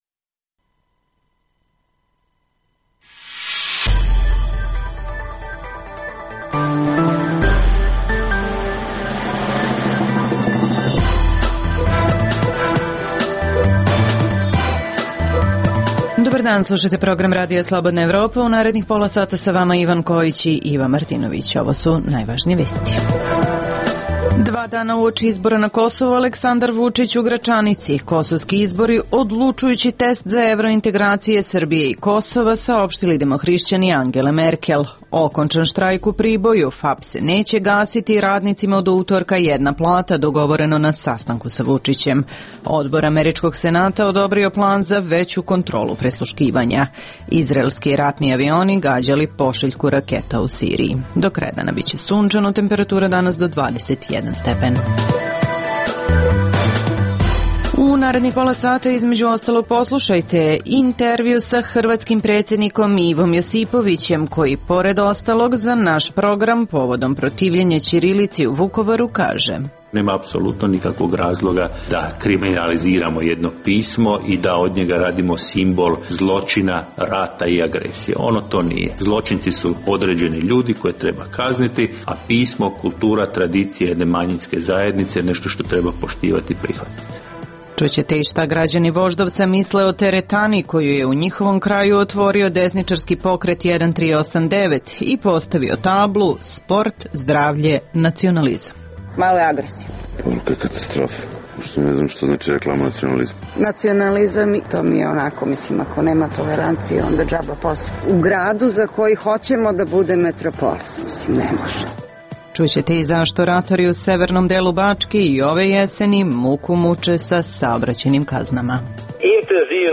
- poslušajte i ekskluzivni intervju koji je za RSE dao predsednik Hrvatske Ivo Josipović. - dogovor u Priboju, radinici FAP-a prekinuli blokadu pruge.